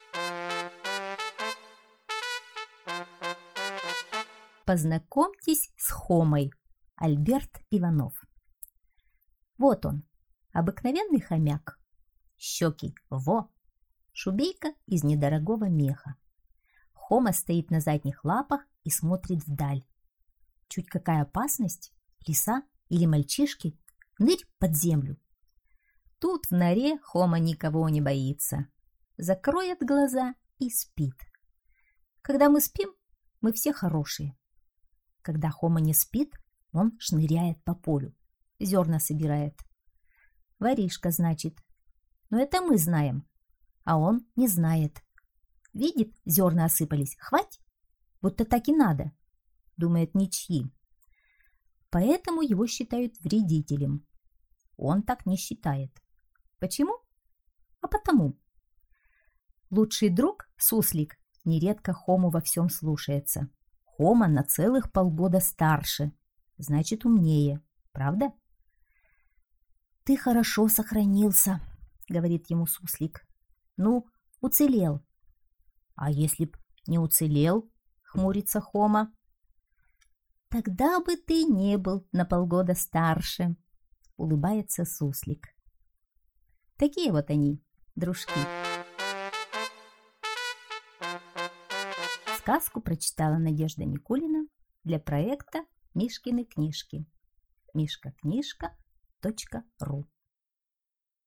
Познакомьтесь с Хомой - аудиосказка Иванова А.А. Знакомство с обыкновенным хомяком Хомой, который живет в норе, а еду ищет на поле...